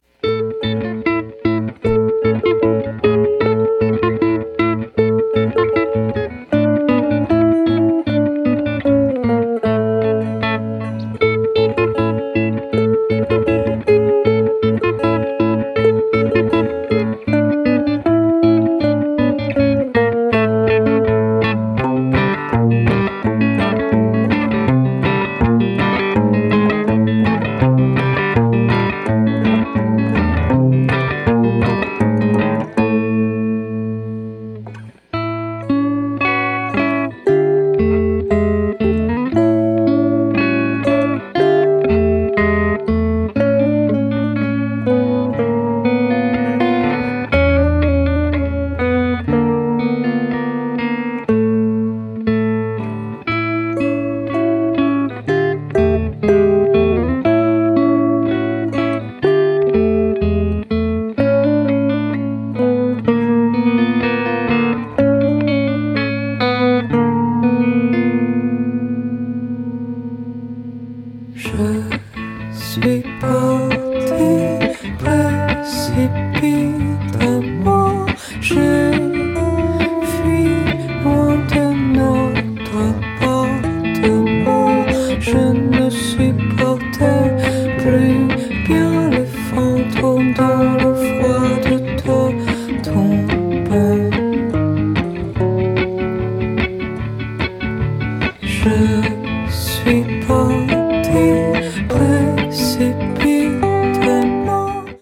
フレンチ・ブルース＆フォークがヴェルヴェッツと出会ったようなモダンな作品！
暗くもなければ明るくもない、地味でもなければ派手でもないこの何とも言えない豊かな味わいをご堪能ください！